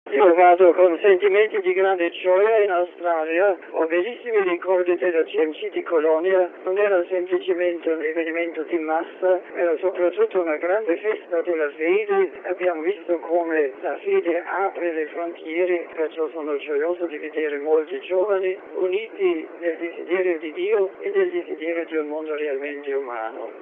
Conversando con i giornalisti a bordo del B777 dell'Alitalia - decollato questa mattina alle 10.30 dall'aeroporto di Fiumicino - Benedetto XVI ha toccato alcuni dei temi di stretta attualità - come la tutela delle risorse della terra - o già affrontati nel corso della sua visita negli Stati Uniti, come quello degli abusi sessuali all'interno della Chiesa.
Ma ascoltiamo un passaggio del dialogo del Papa con i cronisti: